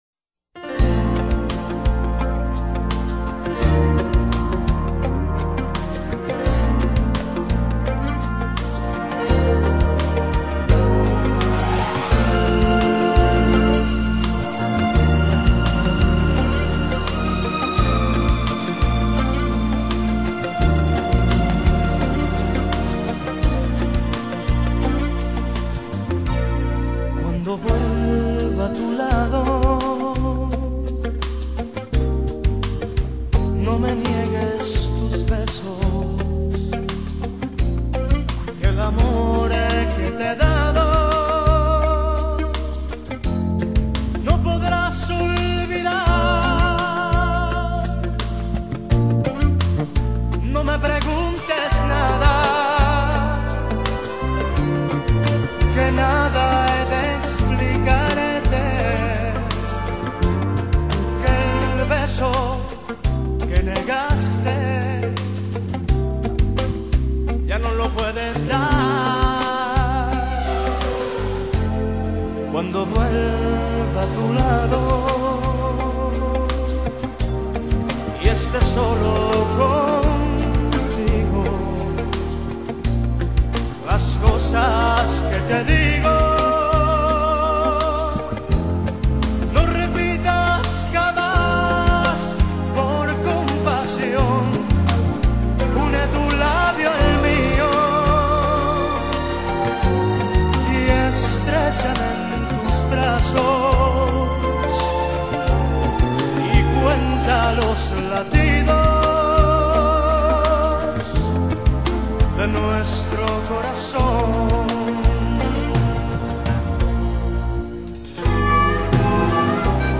Rocks y boleros